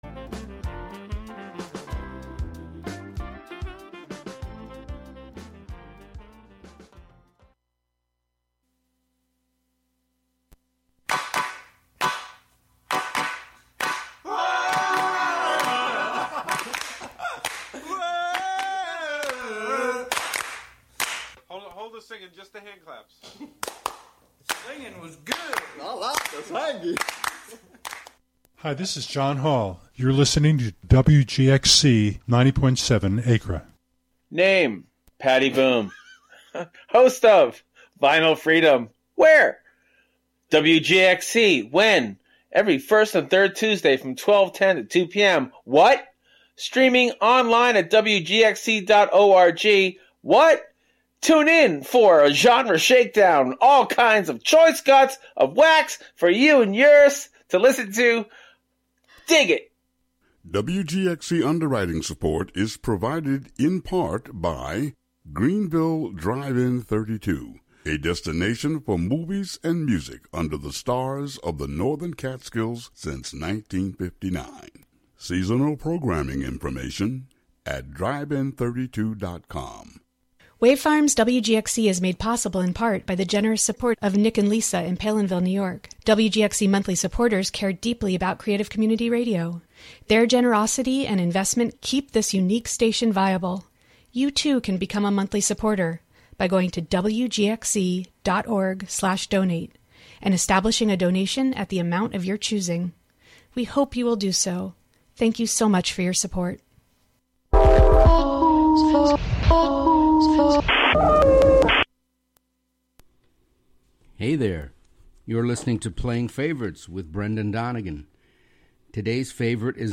the Chicago-based instrumental band Torto...